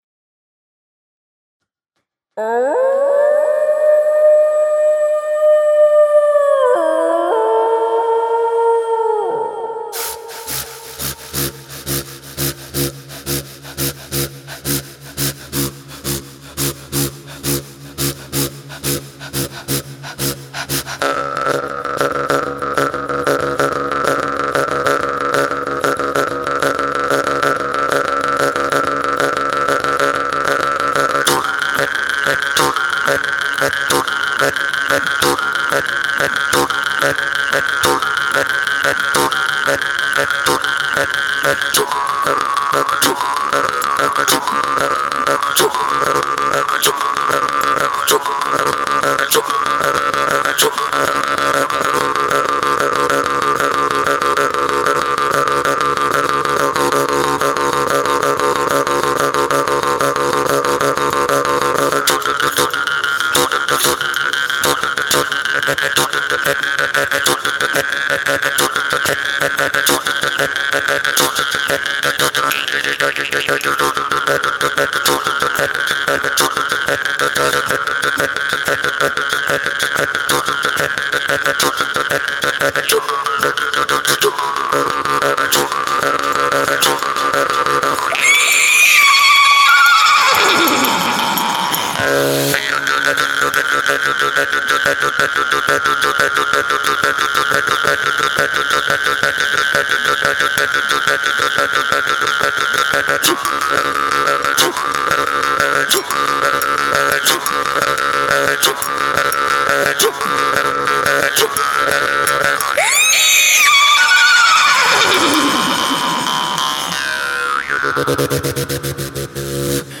Live Jew's-Harp
Genre: Electronic.